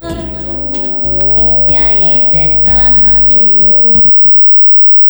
Multitap delay
first delay tap at 100 ms, first delay tap gain 0.5, second delay tap at 300 ms, second delay tap gain 0.3, third delay tap at 400 ms, third delay tap gain 0.2, fourth delay tap at 800 ms, fourth delay tap gain 0.1